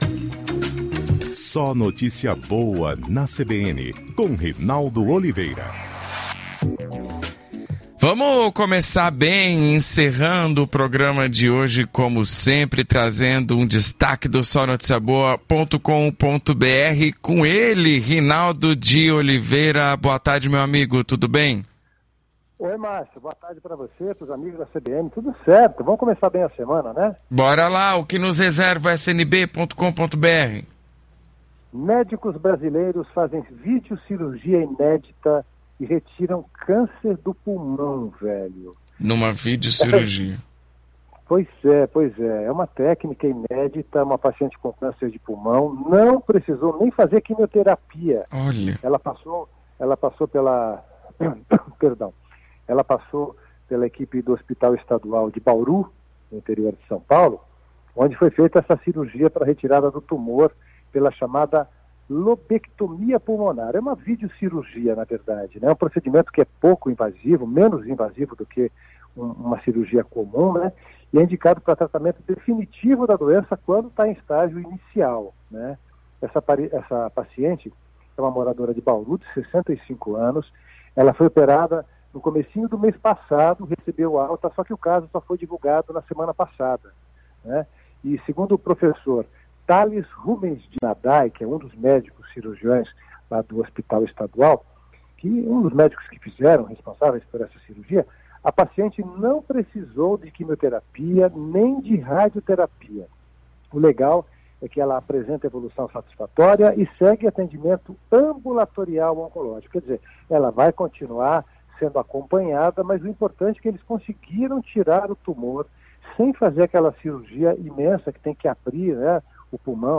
O quadro SNB na CBN vai ao ar de segunda a sexta às 16:55 na rádio CBN Grandes Lagos.